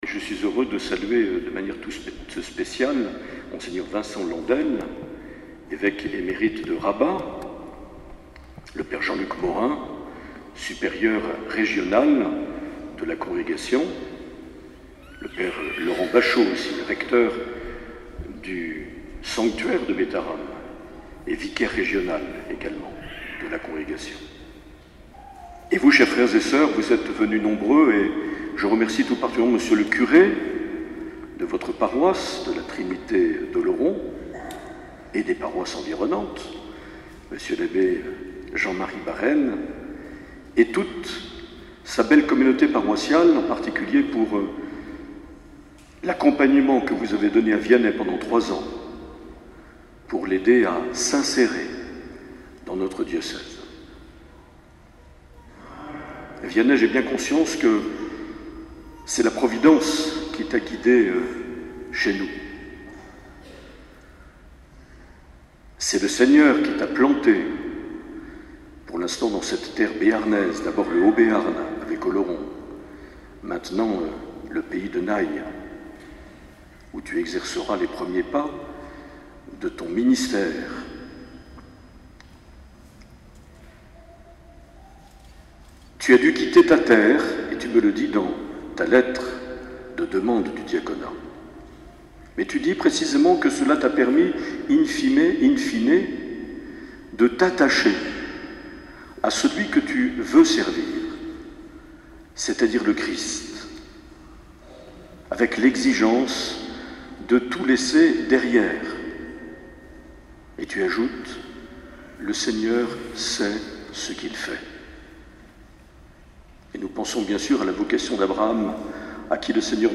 25 novembre 2018 - Cathédrale d’Oloron - Ordinations diaconales
Les Homélies
Une émission présentée par Monseigneur Marc Aillet